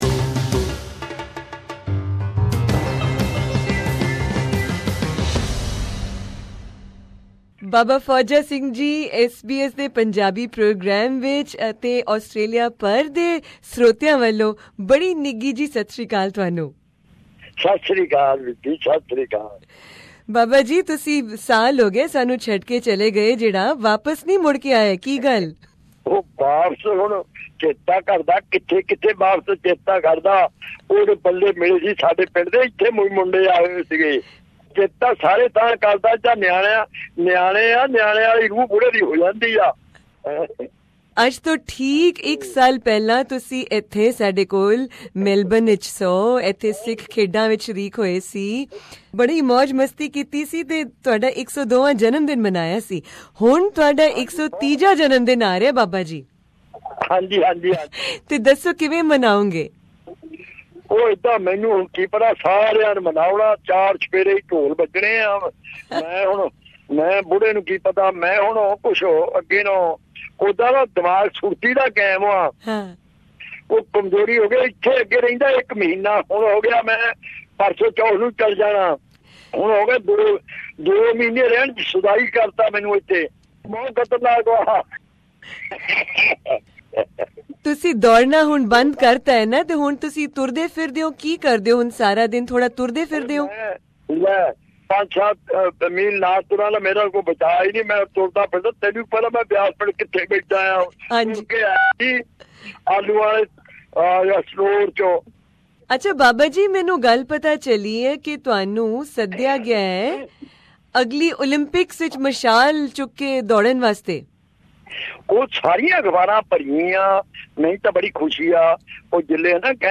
SBS Punjabi program contacted him in India on this special occasion, where he has been visiting for the past two months.